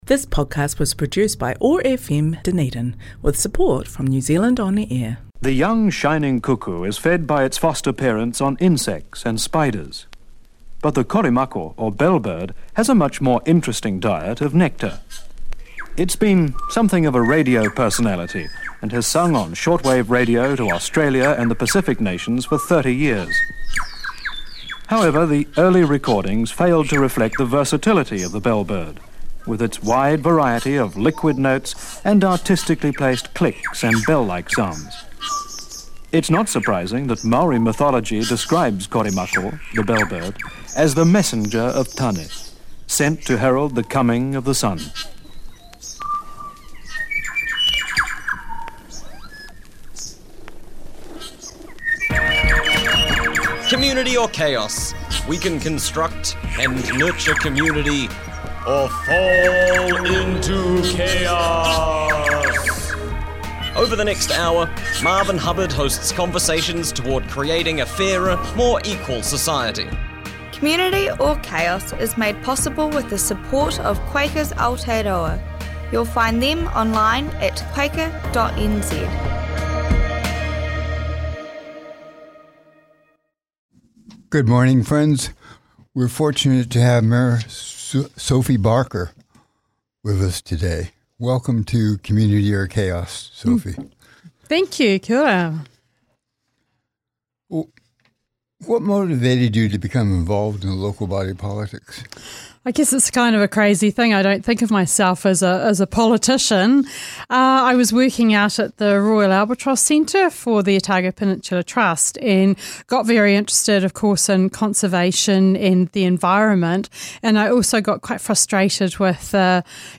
Broadcast on OAR 105.4FM Dunedin